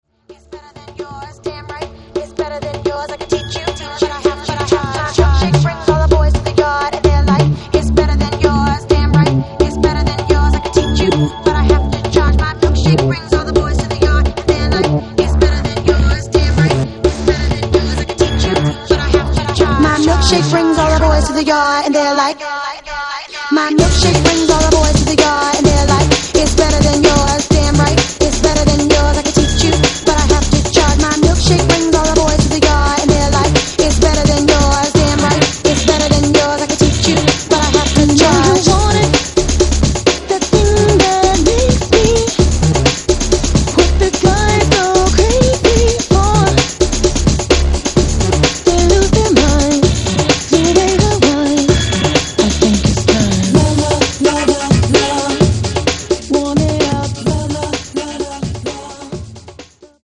Hip Hop.